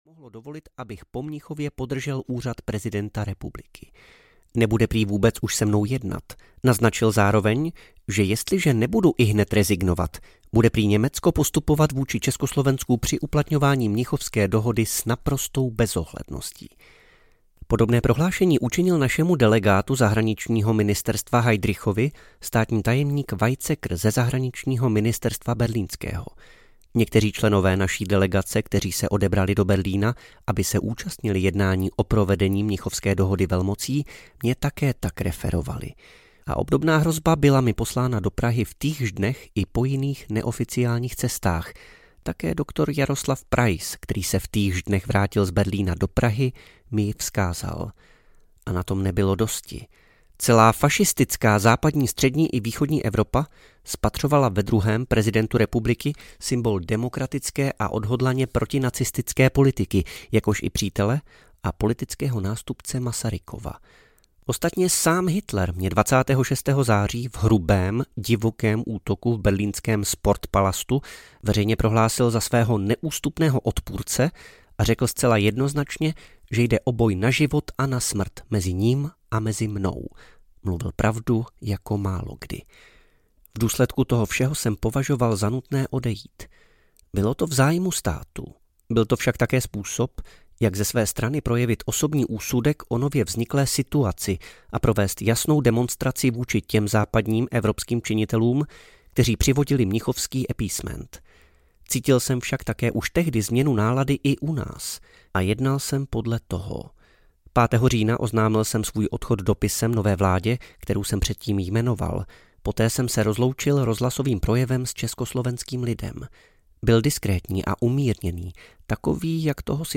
Paměti - část 2: Začíná válka audiokniha
Ukázka z knihy